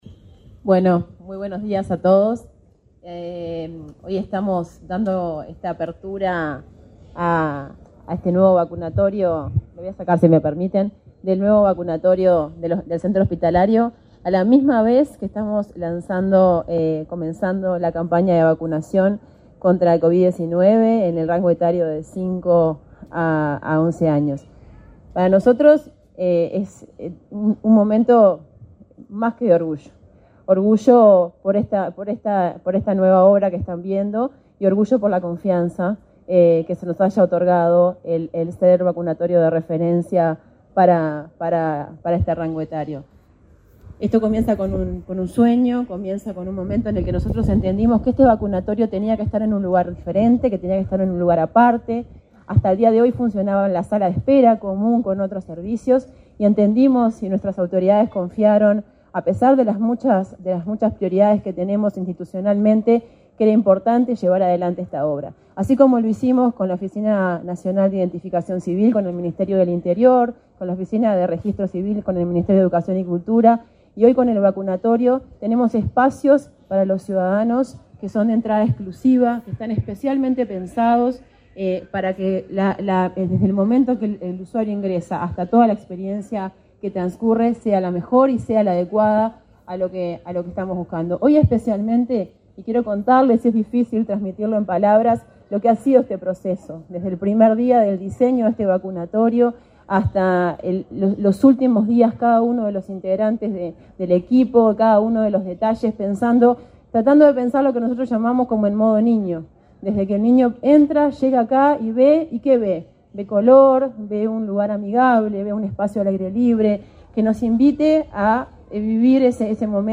Conferencia de prensa por inauguración de vacunatorio en Centro Hospitalario Pereira Rossell